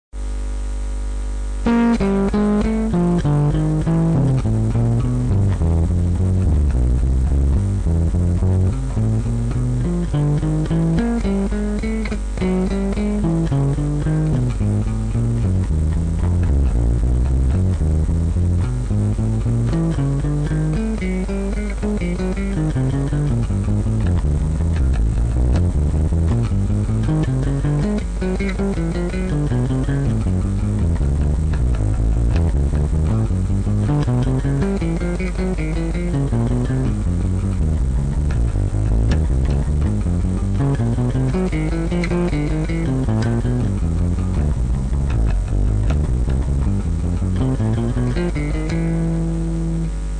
rythme à appliquer:  croche ou double croche avec un tempo donné par le métronome